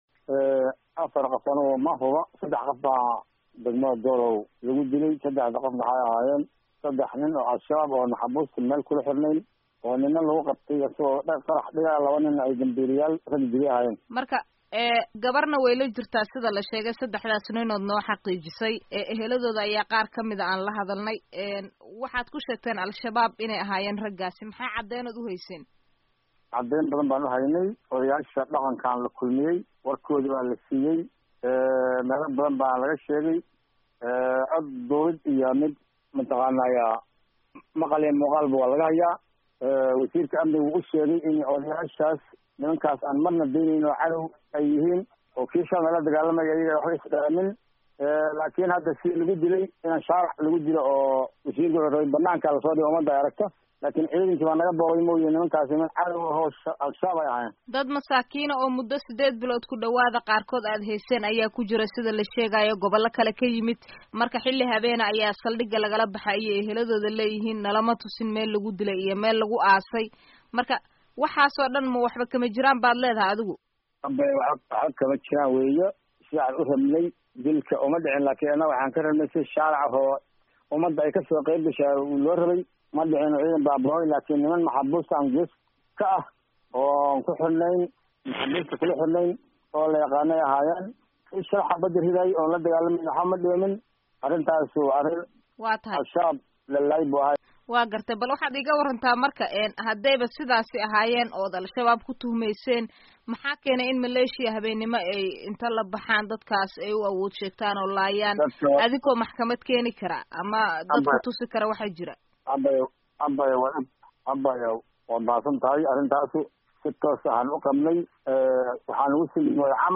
Wareysi: Guddoomiyaha Doolow